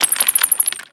Cri de Crabicoque dans Pokémon X et Y.